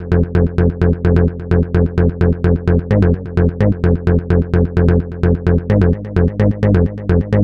Index of /90_sSampleCDs/Club_Techno/Bass Loops
BASS_129_F.wav